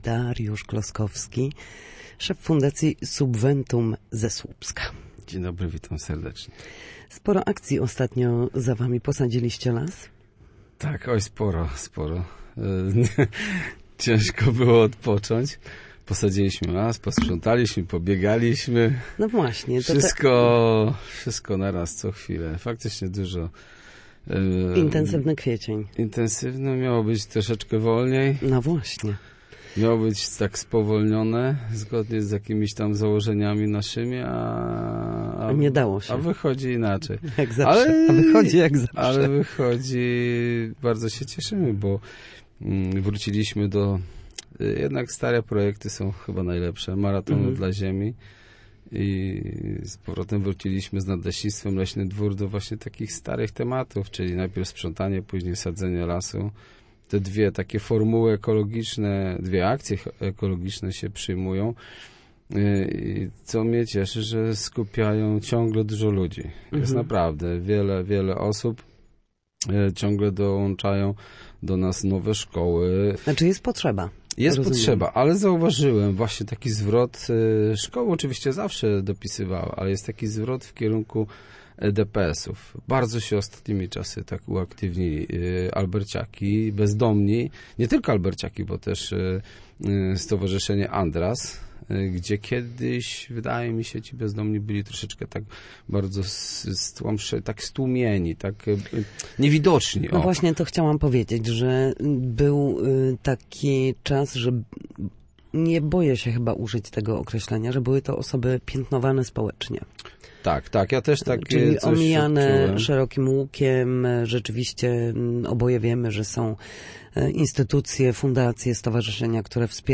Na naszej antenie podsumował kwietniowe działania.